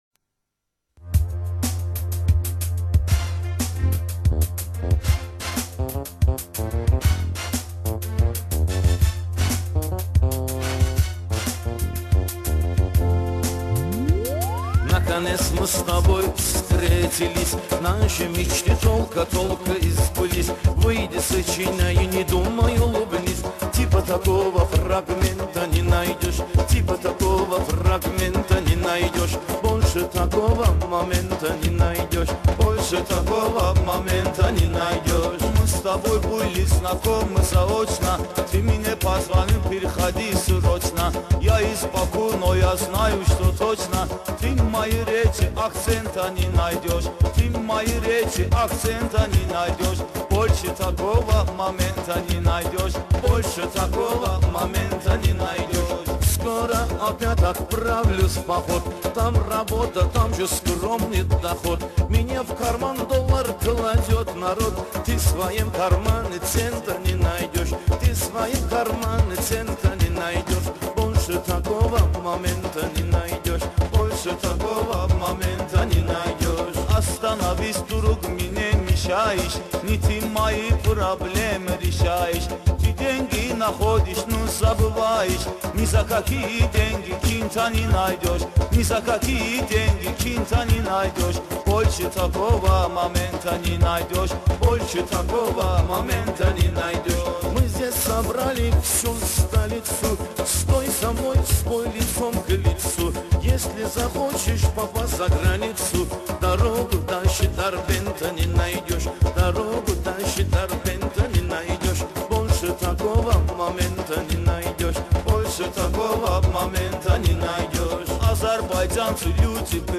Мейхана на русском.
Куплеты исполнялись под ритмический аккомпанемент дэфа.